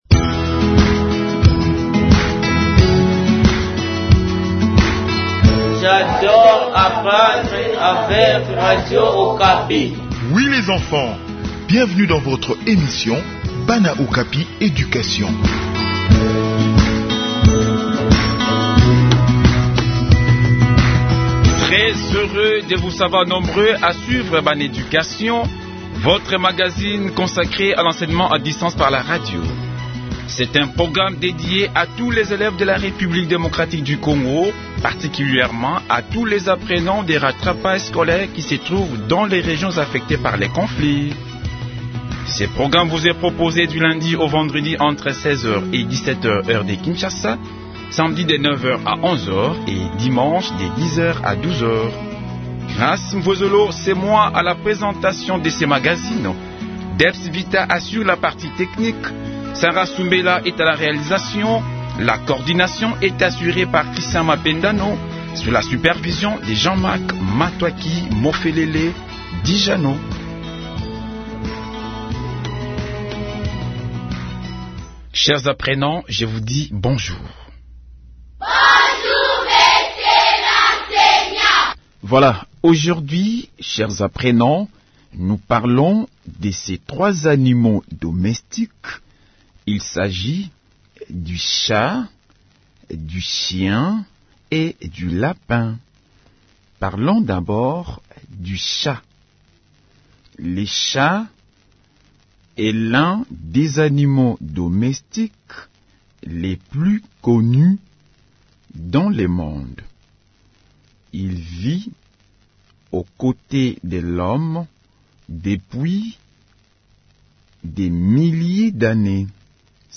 Enseignement à distance: leçon sur les chat, chien et lapin